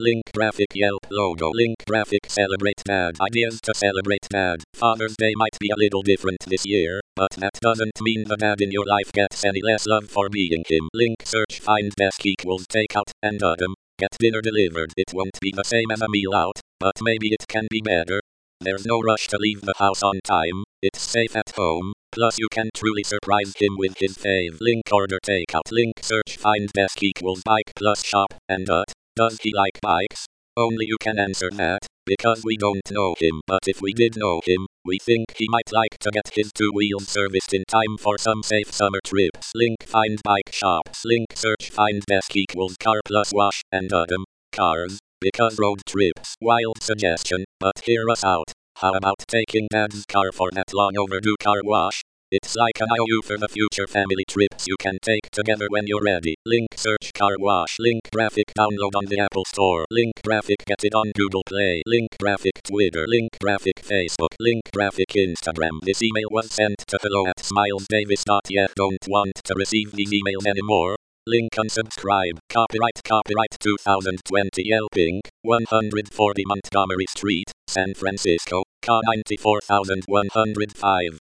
Text to voice recording and transcript for hearing impaired.